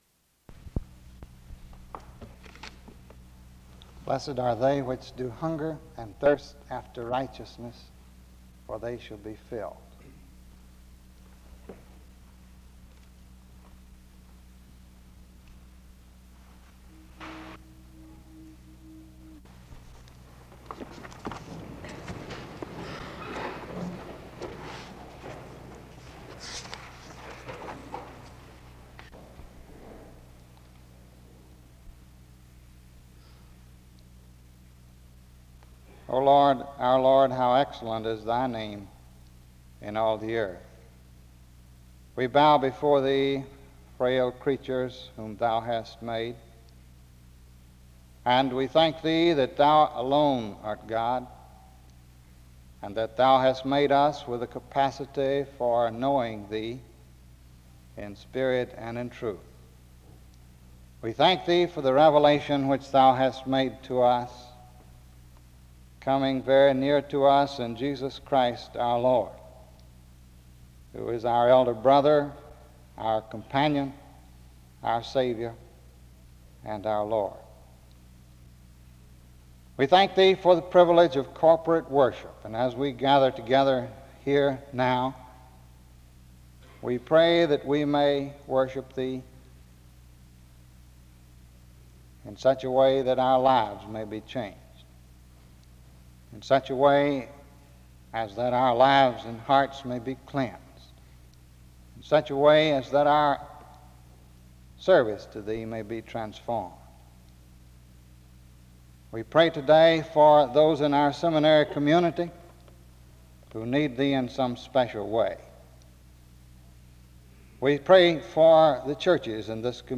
Download .mp3 Description After the reading of Matthew 5:6, a prayer, and introduction (start-4:08)
SEBTS Chapel and Special Event Recordings SEBTS Chapel and Special Event Recordings